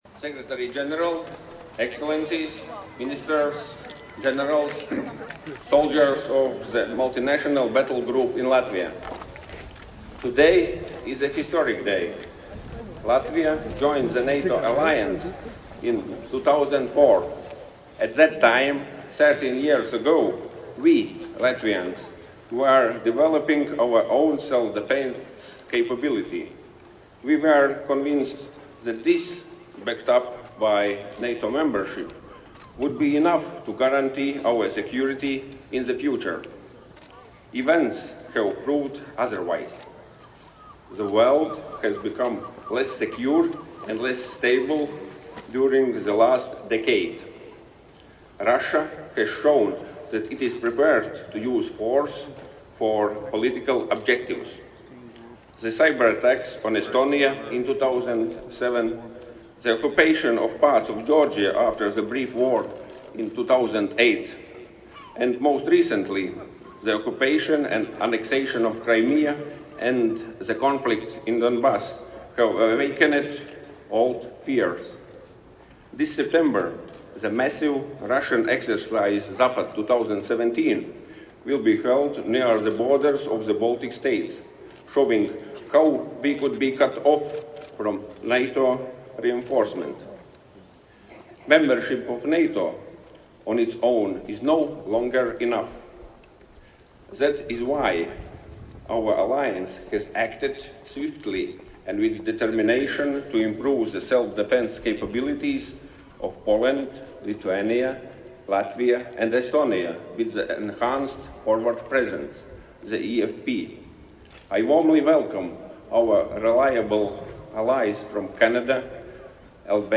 Remarks by NATO Secretary General Jens Stoltenberg at the Latvian eFP Battlegroup opening ceremony, Camp Ādaži, Latvia
(As delivered)